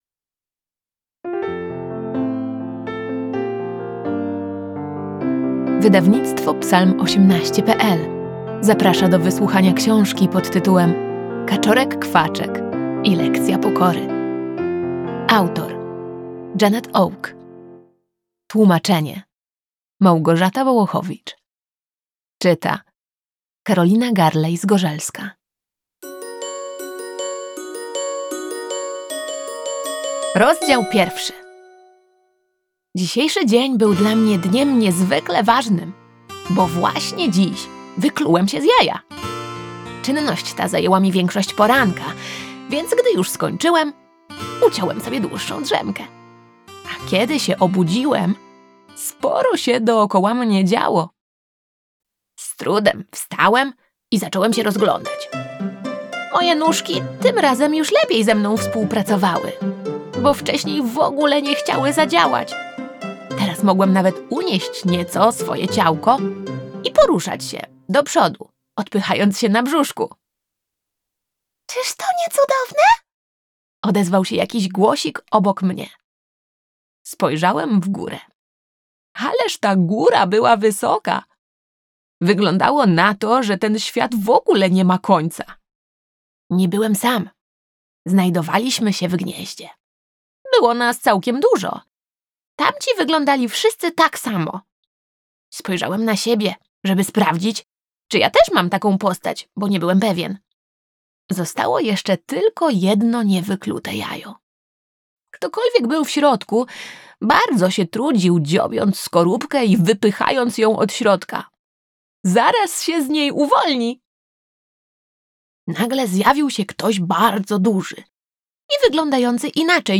Kaczorek Kwaczek i lekcja pokory - Audiobook